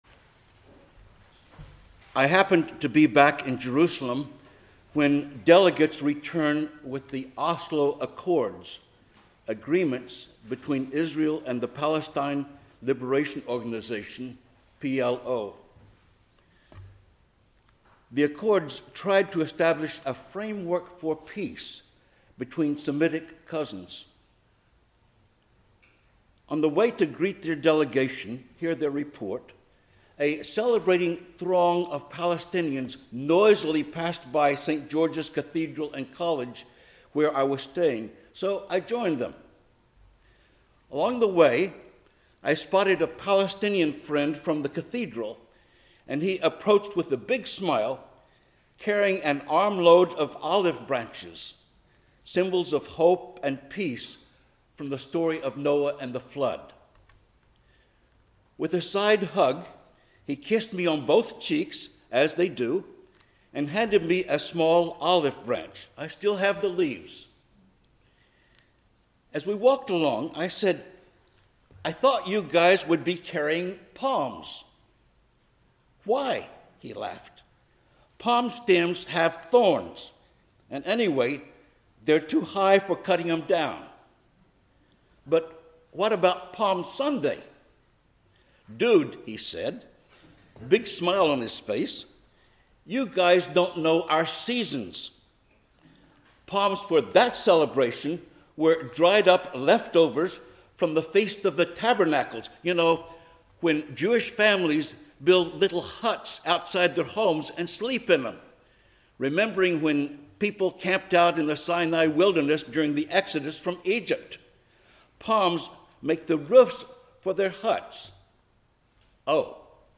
Service Type: 10:00 am Service